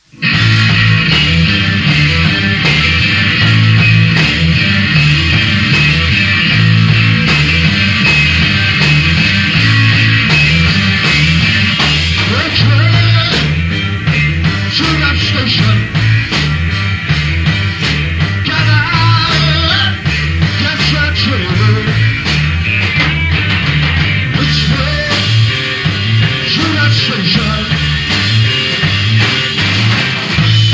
Heavy Metal Rock.